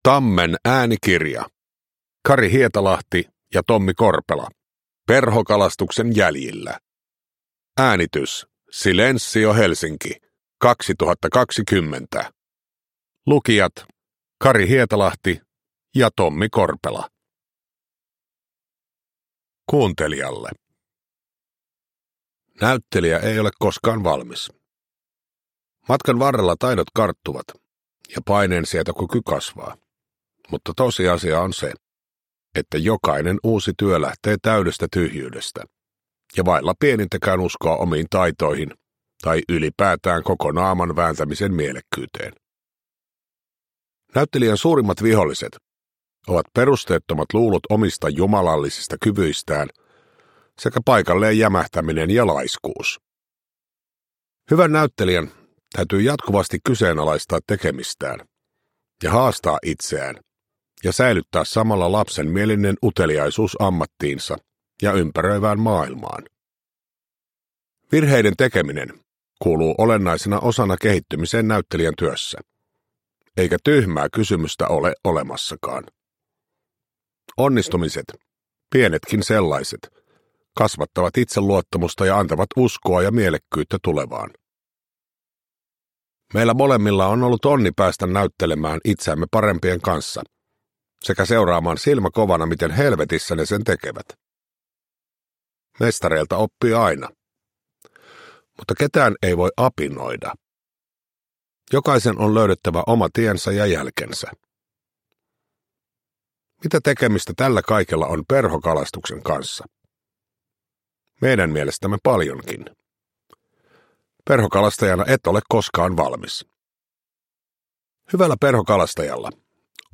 Perhokalastuksen jäljillä – Ljudbok – Laddas ner
Uppläsare: Tommi Korpela, Hissu Hietalahti